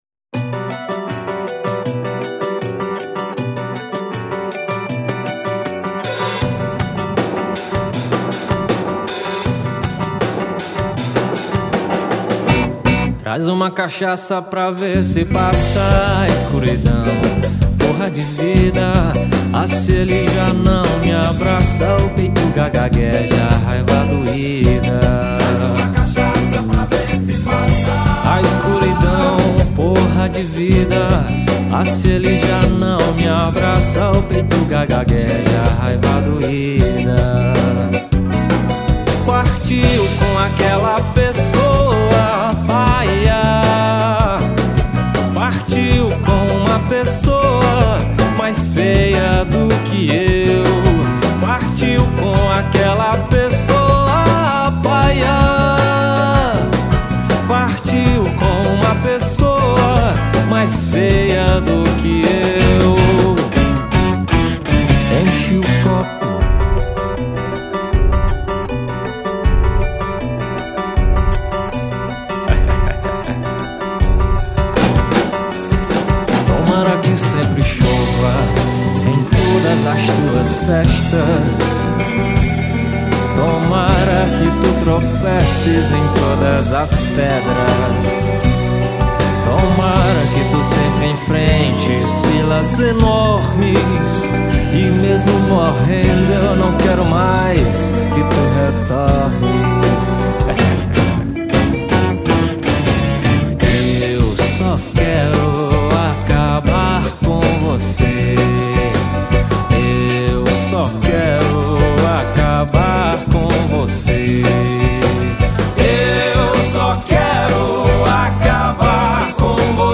1914   04:13:00   Faixa:     Rock Nacional